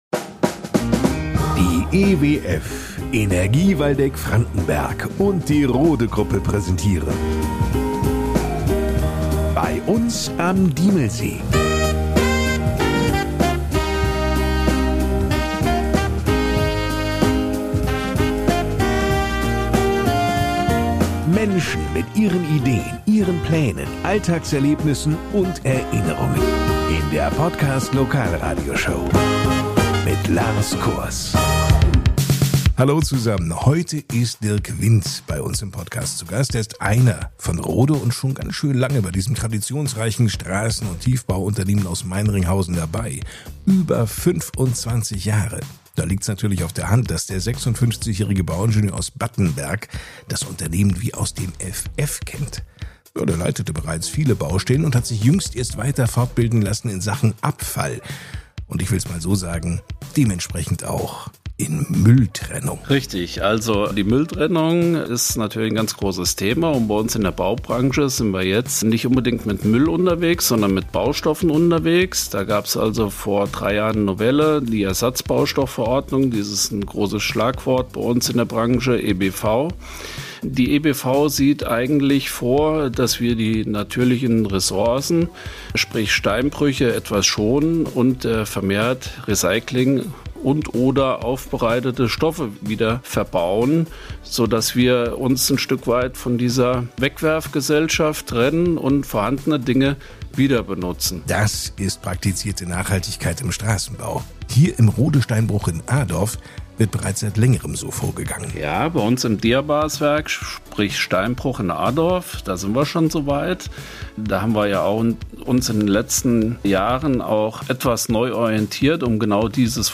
Die Podcast-Lokalradioshow für die schönste Gemeinde im Upland